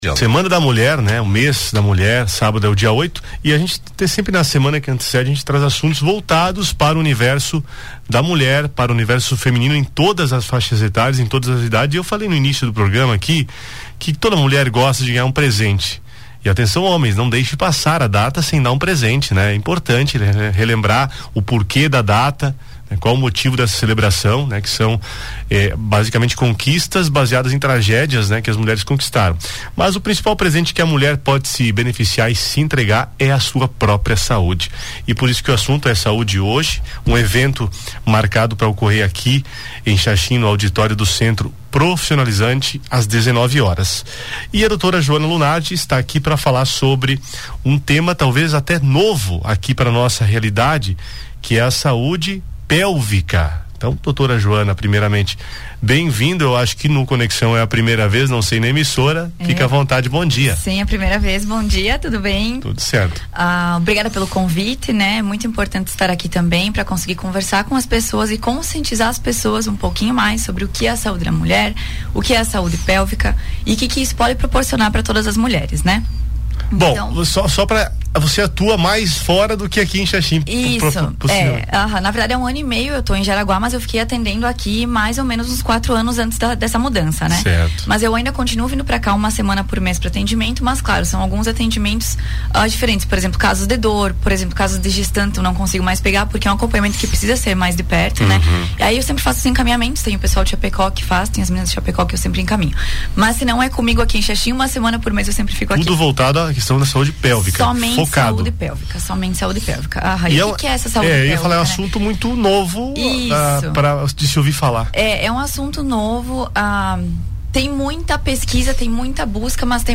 No Conexão Entrevista desta quarta-feira